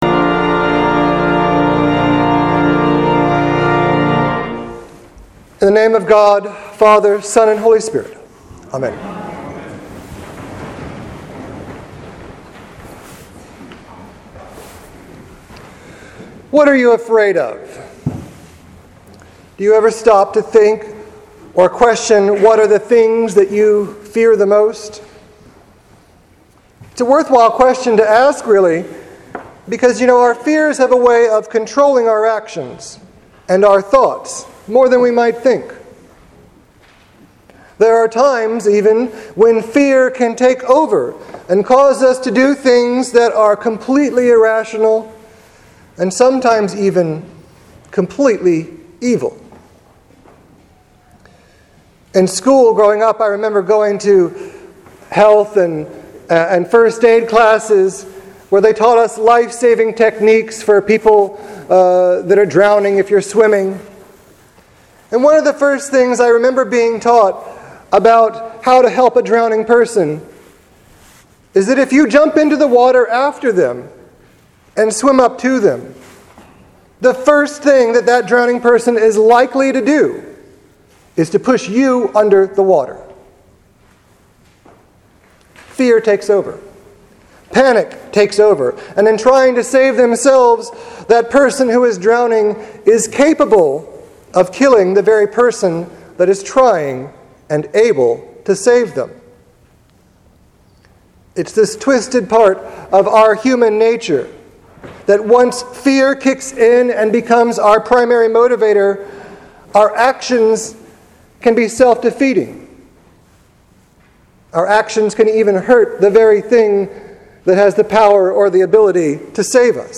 What are you afraid of? Sermon for June 21st, 2015